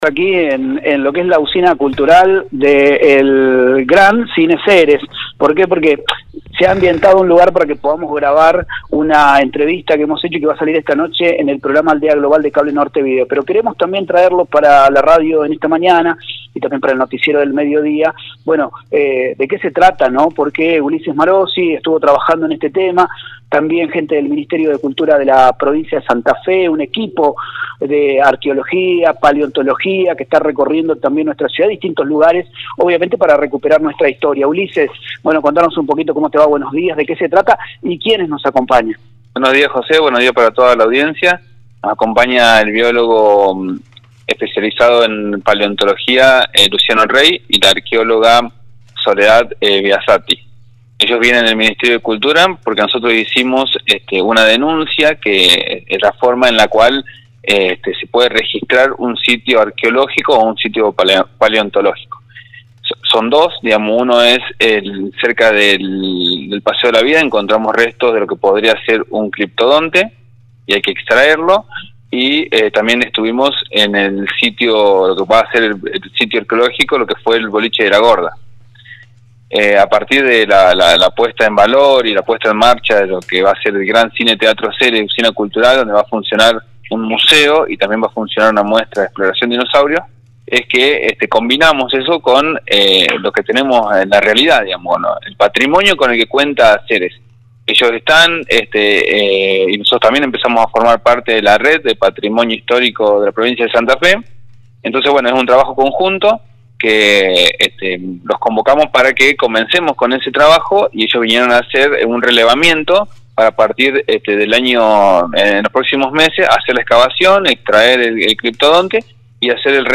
Desde la Usina Cultural, Gran Cine Teatro Ceres